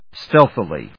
音節stéalth・i・ly 発音記号・読み方
/‐θɪli(米国英語)/